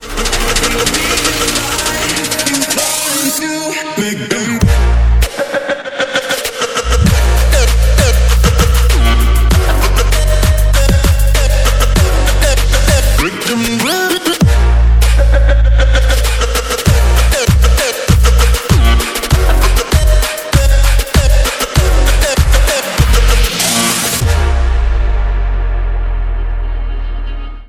• Качество: 128, Stereo
жесткие
мощные басы
Trap
качающие
взрывные